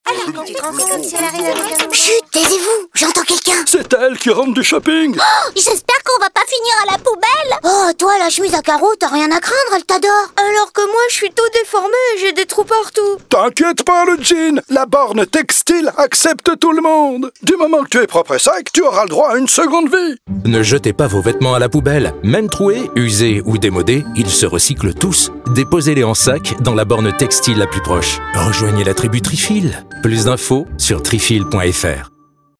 Spot radio TEXTILES Tryfil juin 2021.mp3